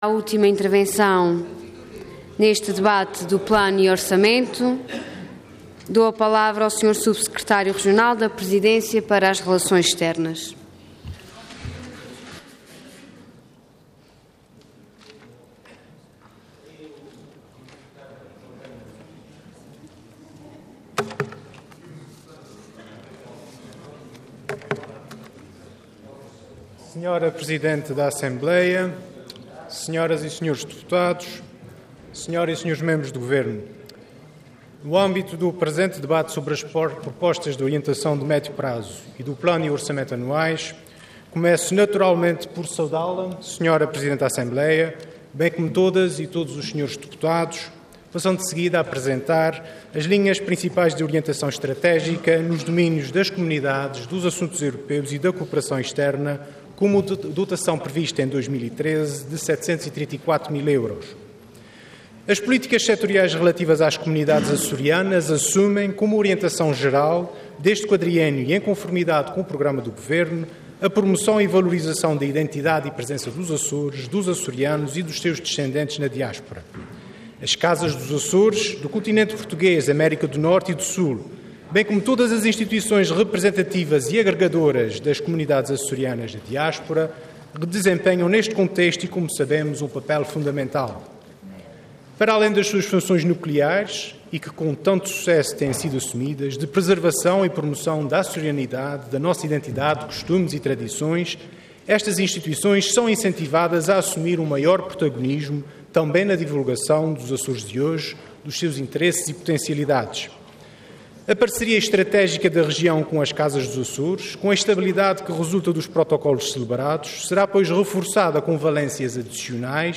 Detalhe de vídeo 21 de março de 2013 Download áudio Download vídeo Diário da Sessão X Legislatura Debate relativo ao OMP 2013. Intervenção Intervenção de Tribuna Orador Rodrigo Oliveira Cargo Subsecretário Regional da Presidência Para as Relações Externas Entidade Governo